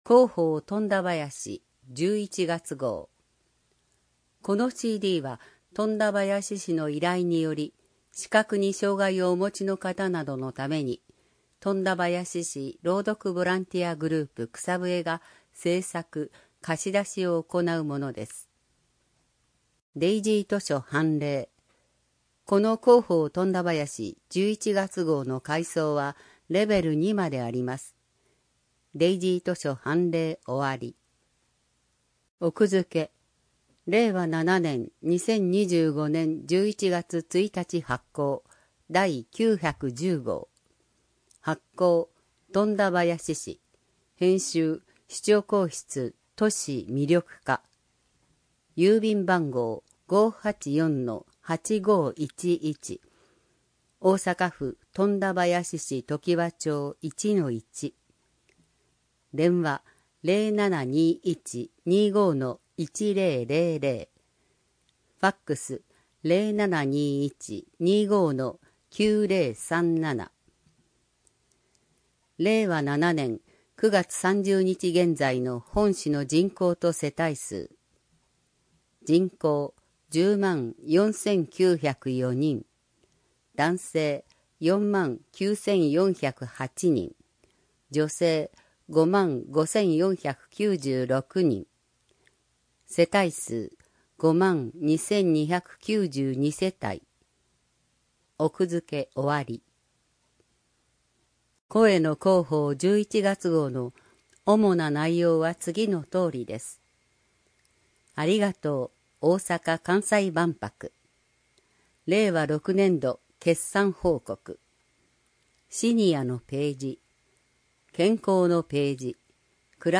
この音声は、本市の依頼により富田林市朗読ボランティアグループ「くさぶえ」が視覚に障がいをお持ちの人などのために製作しているものです（図やイラストなど一部の情報を除く）。